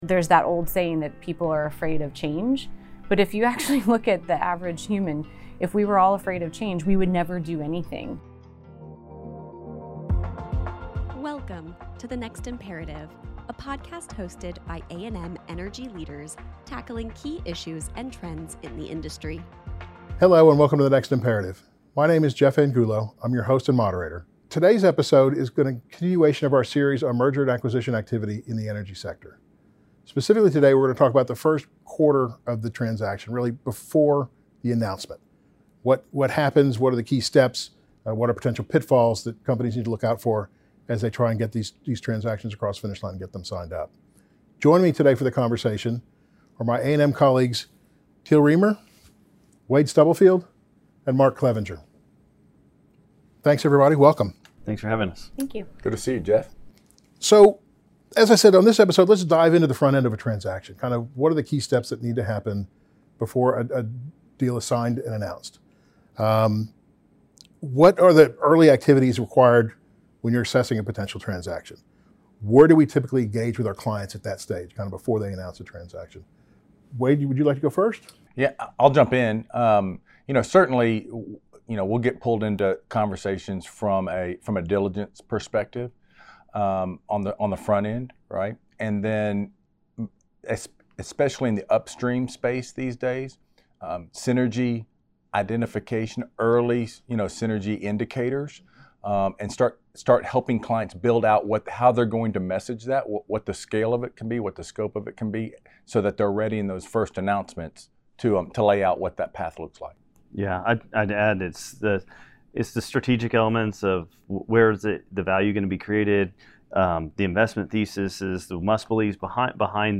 Listen in as these industry experts share their tips and advice on preparing for the pre-close phase of a deal. Learn how to deal with imperfect information and the importance of communication to your entire team in this phase of the deal.